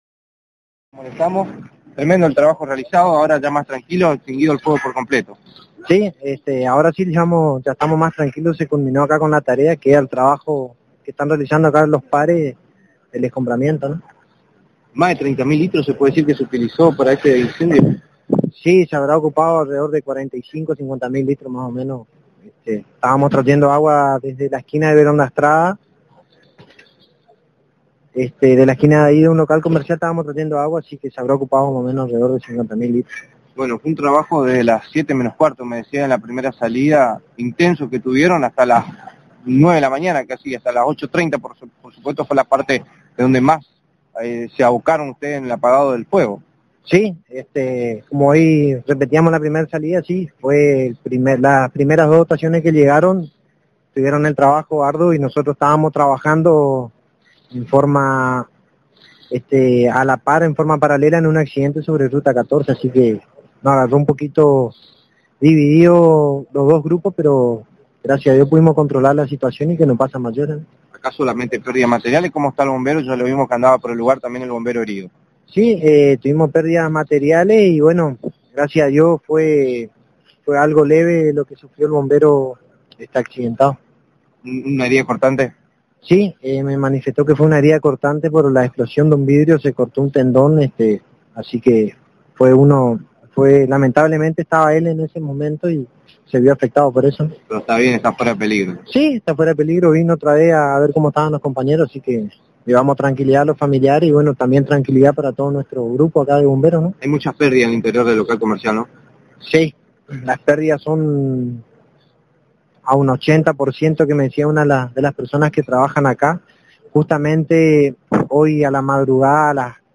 Audio: Entrevista en rueda de prensa